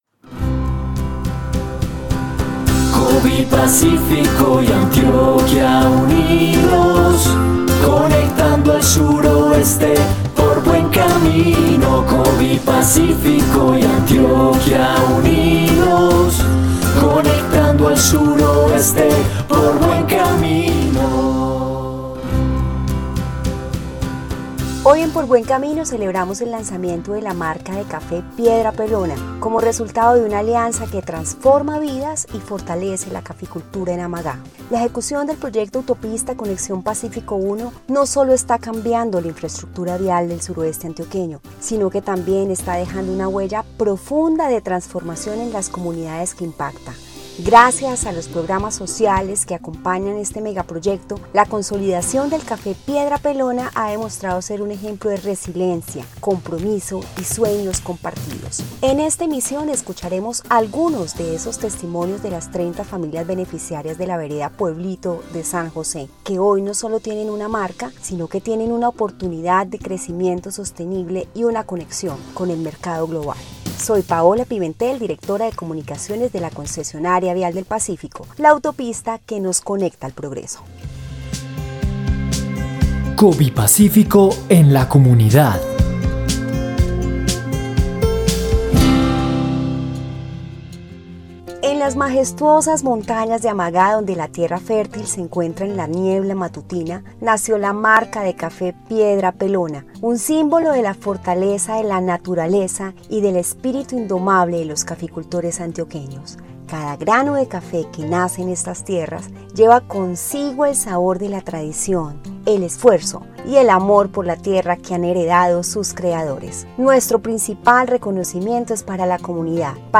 Programa radial No 64 - Diciembre de 2024